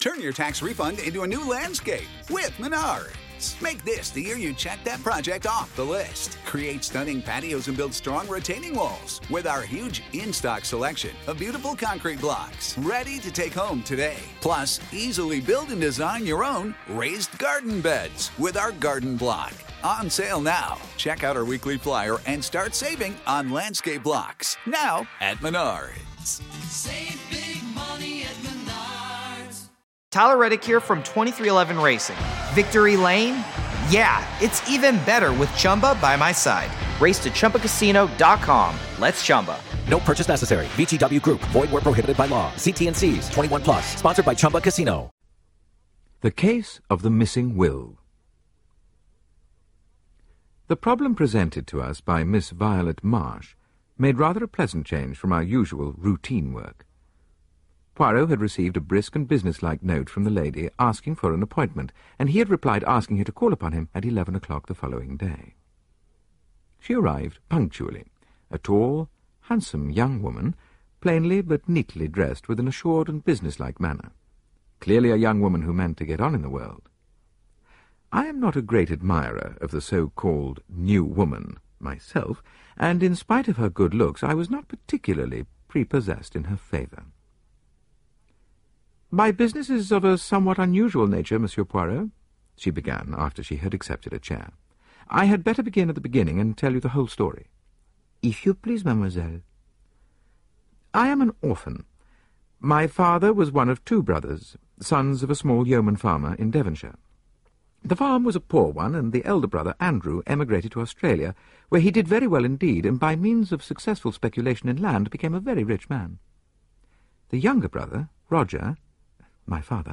Agatha Christie - Hercule Poirot (Audiobook Collection) Podcast - Agatha Christie - Hercule Poirot 21 - The Case of the Missing Will (1922) | Free Listening on Podbean App